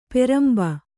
♪ peramba